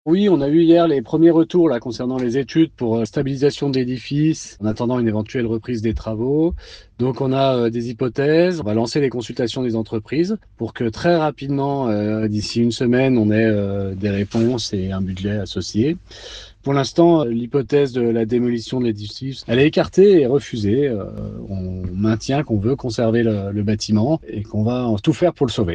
La municipalité attendait de premiers retours d’expertises pour savoir s’il est encore possible ou non de sauver la bâtisse construite en 1602, ou si la destruction semble inévitable. Finalement les derniers retours laissent entrevoir du positif comme l’explique Sidney Contri, adjoint à la mairie de Sallanches en charge de la Mobilité de l’Environnement et de l’Agriculture.